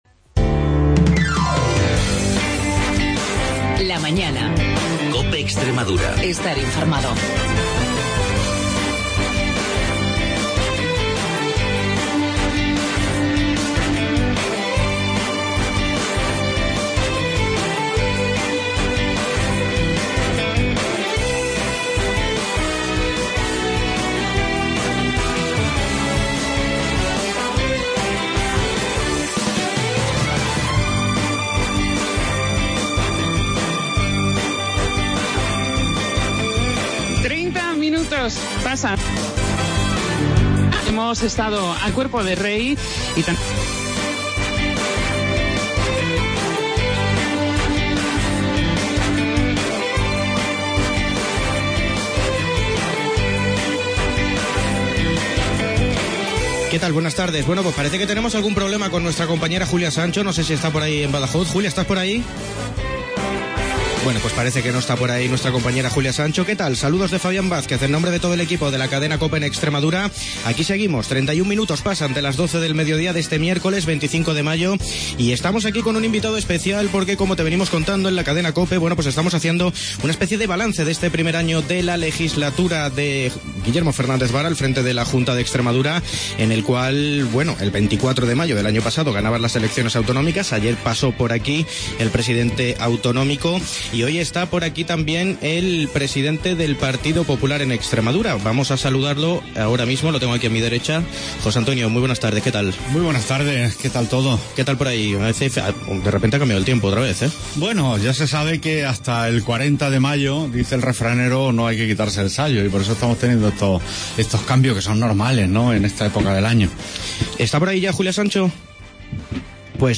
ENTREVISTA AL PTE DEL PP DE EXTREMADURA JOSÉ A MONAGO